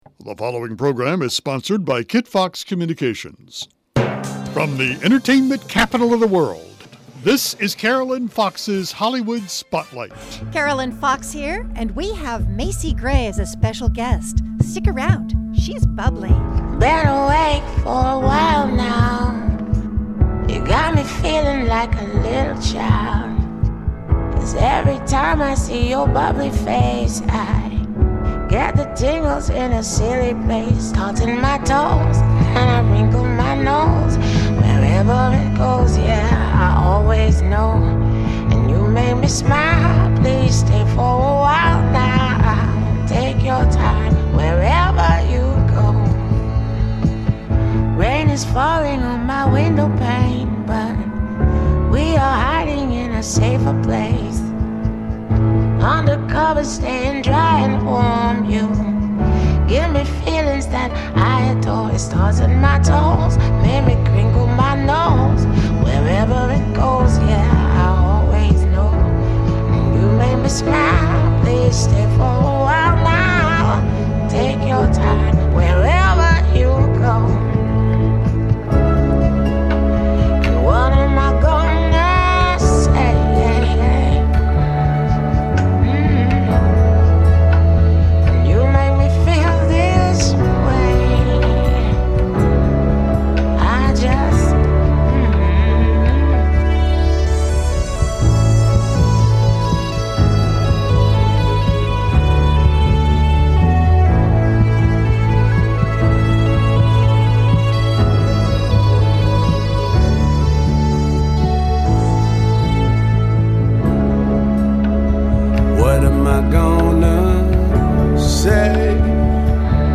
Complete Radio Broadcast featuring Very Special Guest, Macy Gray